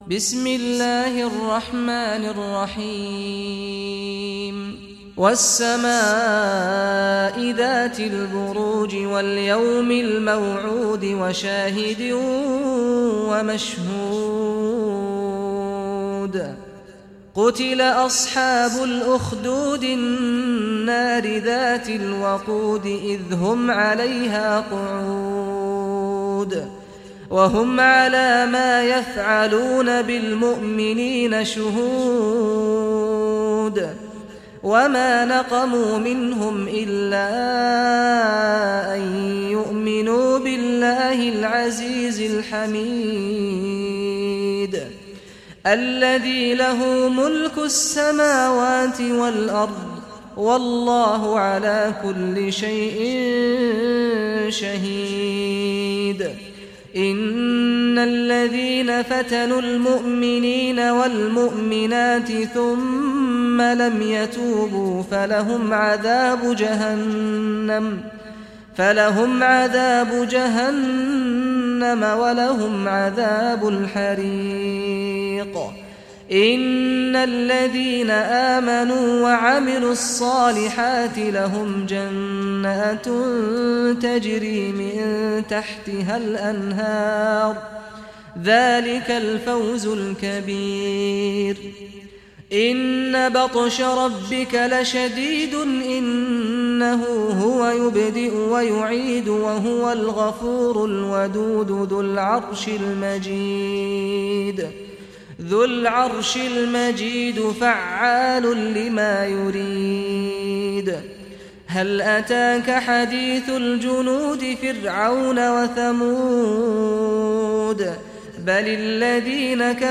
Surah Buruj Recitation by Sheikh Saad al Ghamdi
Surah Buruj, listen or play online mp3 tilawat / recitation in Arabic in the beautiful voice of Sheikh Saad al Ghamdi.